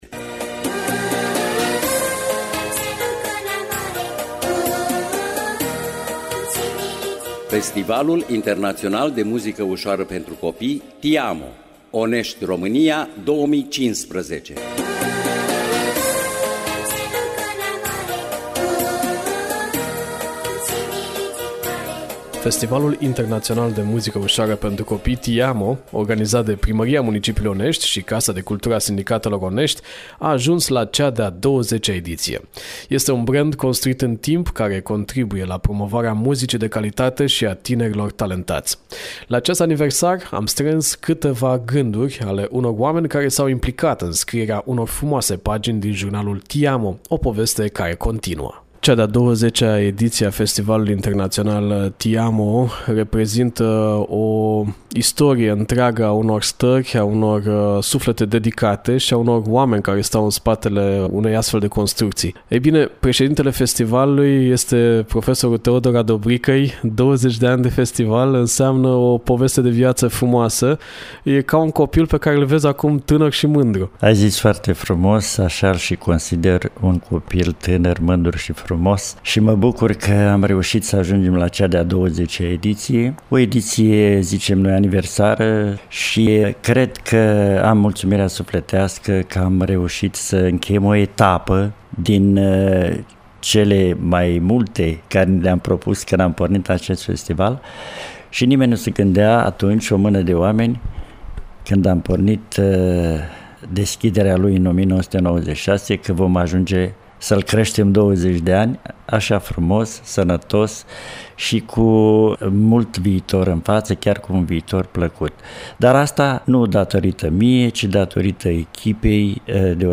REPORTAJ TI AMO 2015
REPORTAJ-TI-AMO-2015.mp3